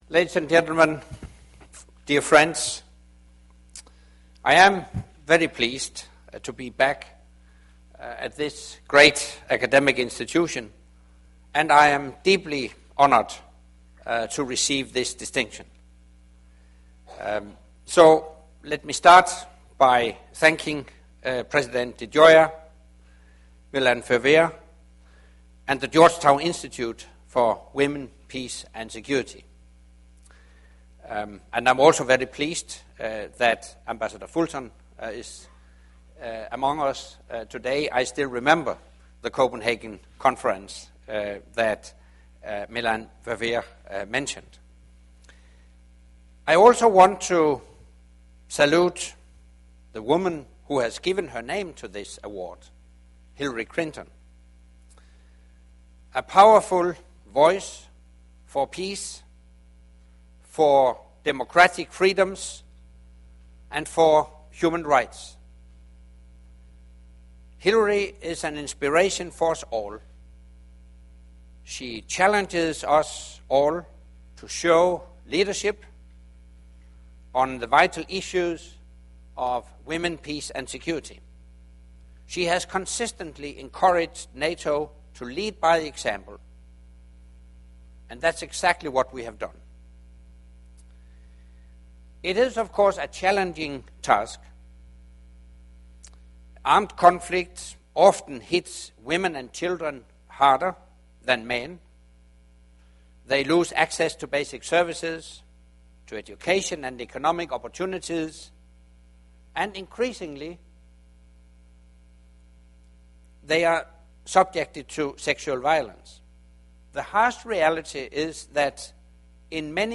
Leading by Example: Women, Peace, Security and NATO - Speech by NATO Secretary General Anders Fogh Rasmussen at Georgetown University in Washington DC